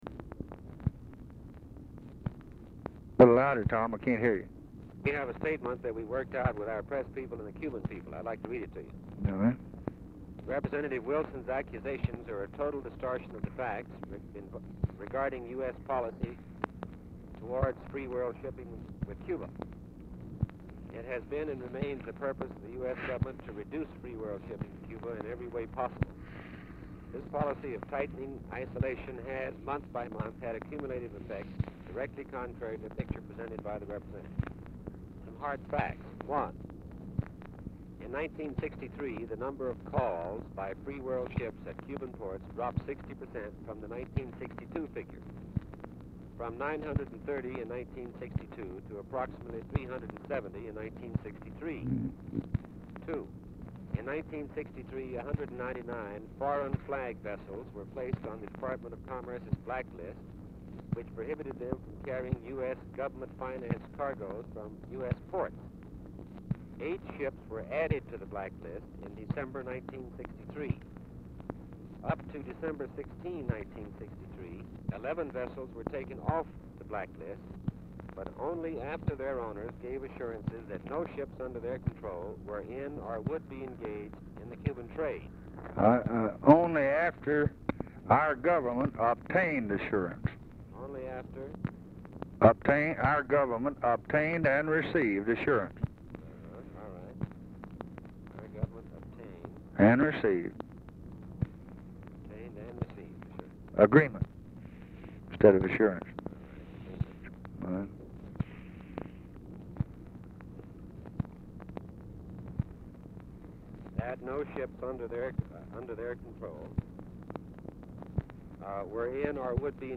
MANN READS AND LBJ EDITS PROPOSED STATEMENT CONCERNING US EMBARGO OF SHIPS TRADING WITH CUBA IN RESPONSE TO CHARGES BY REPUBLICAN CONGRESSMAN BOB WILSON; STATE DEPT MEETING WITH LONGSHOREMEN, MARITIME LABOR UNIONS; US POSITION ON EXPORTING WHEAT TO USSR
White House Telephone Recordings and Transcripts
Oval Office or unknown location
Telephone conversation
Dictation belt